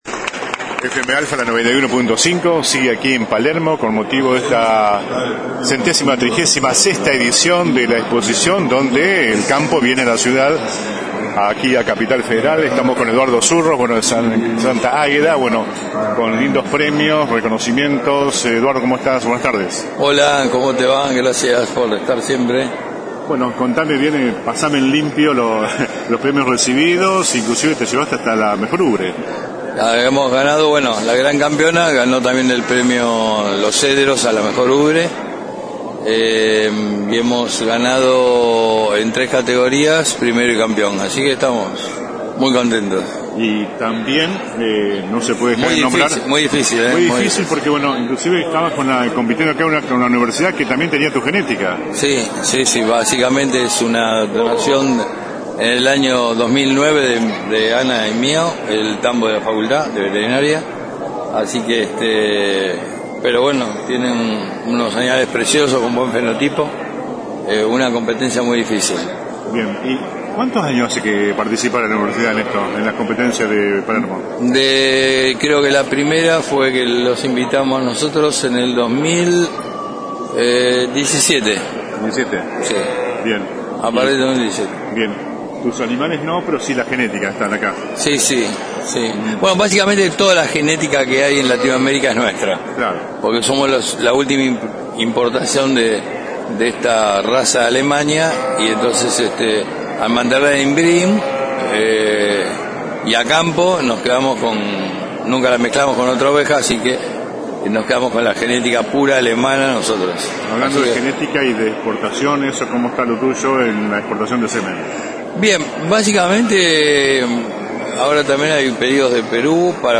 (galería de imágenes) La 91.5 dice presente una vez más en la mayor muestra del campo del país.